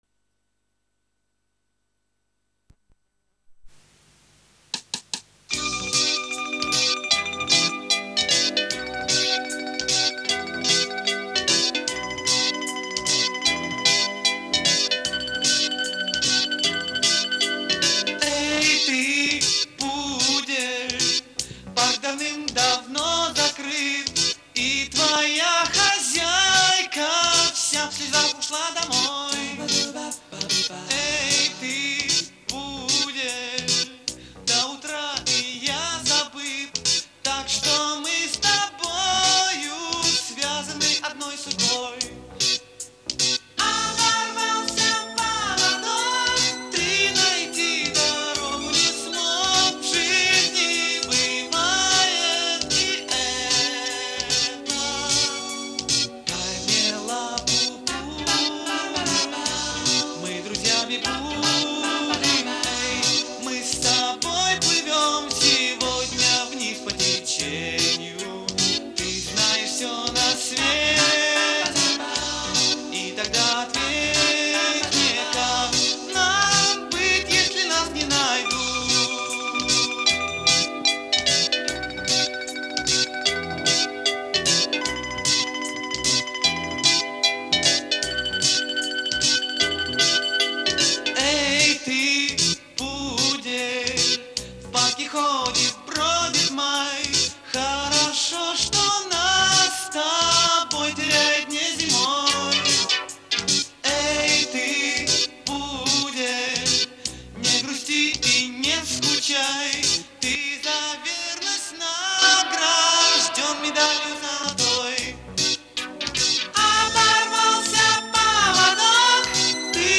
На касете звучит намного лучше, чем оцифровка.